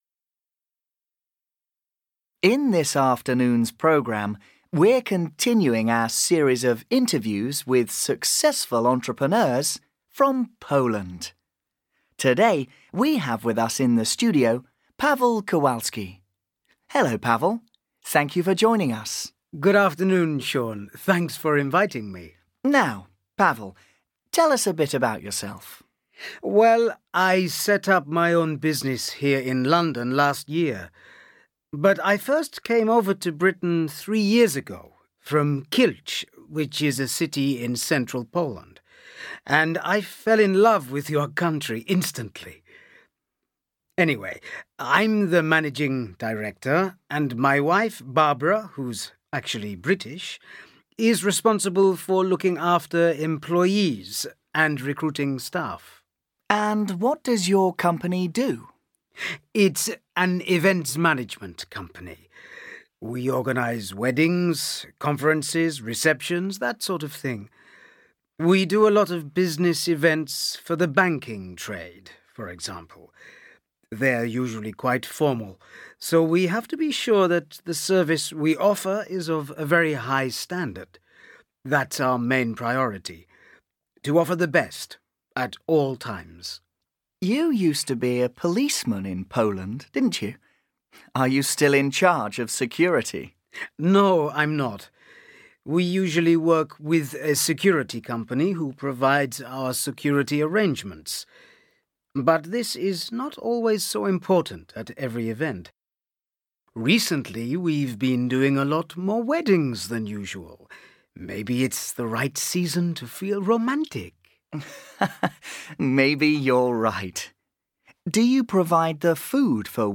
Section IV: Listening
Listen to a man talking about his business.